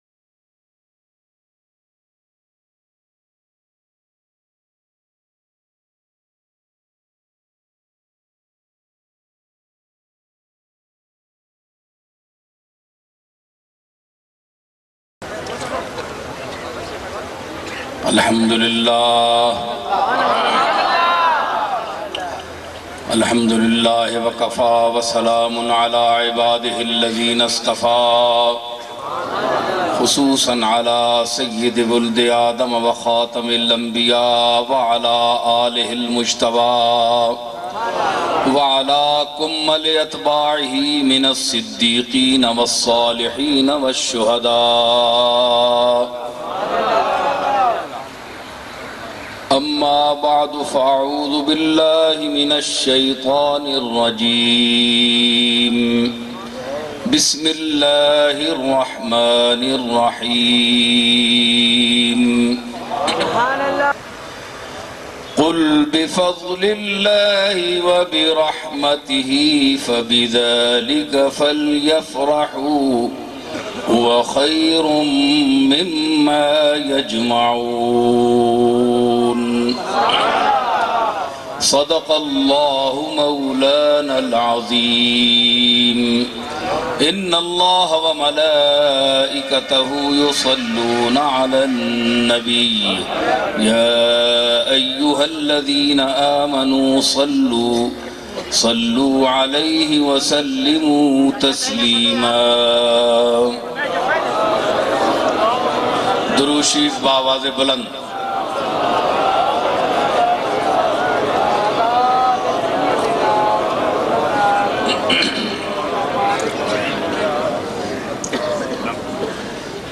Jashan-E-Eid Milad un Nabi bayan mp3